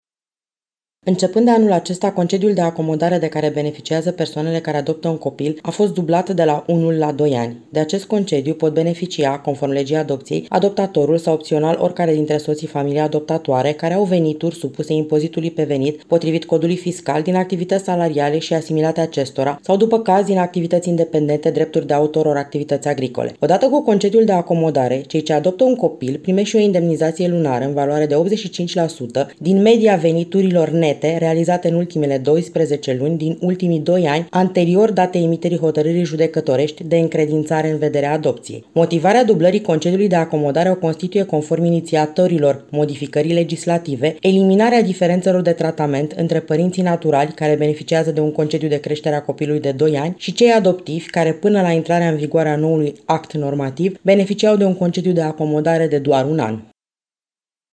Sursa: Radio Romania Brasov FM